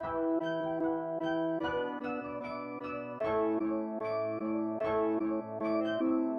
奇怪的循环
标签： 150 bpm Hip Hop Loops Piano Loops 1.08 MB wav Key : Unknown FL Studio
声道立体声